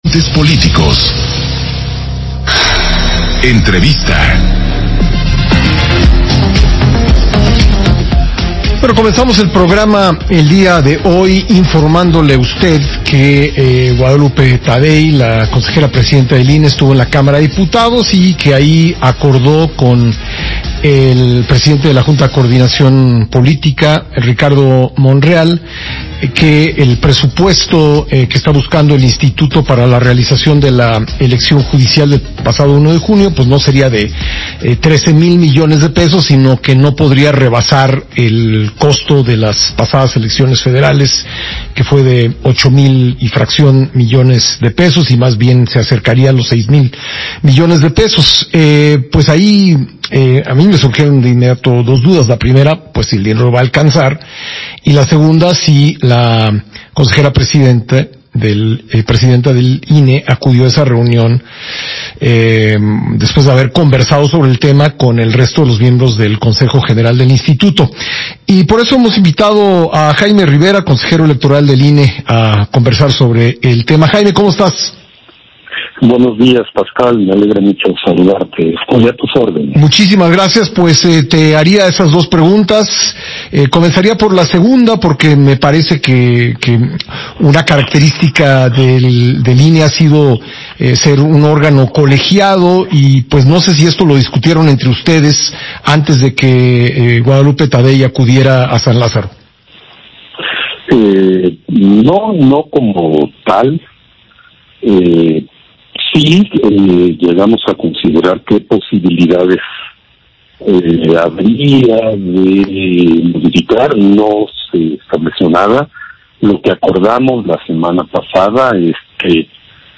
Entrevista del Consejero Electoral Jaime Rivera con Pascal Beltrán para Grupo Imagen